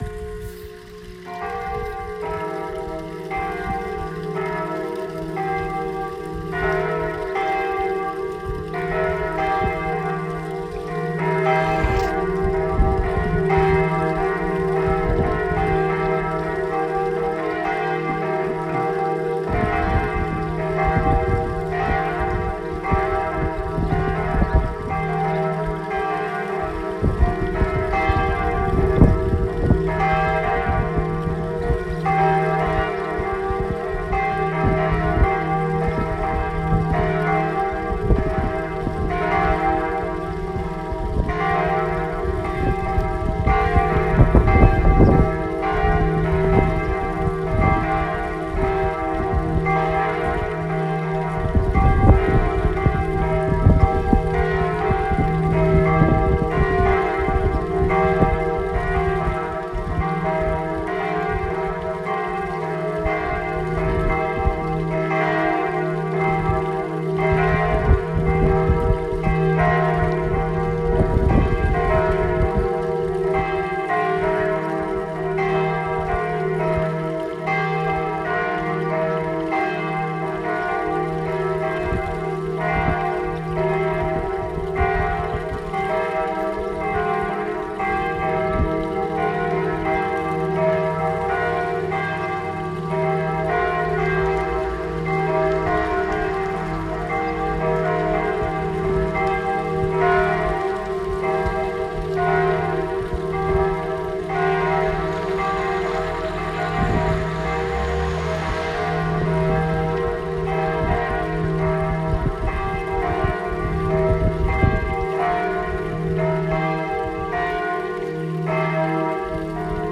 son des cloches
cloches-nouvelle-sonnerie.m4a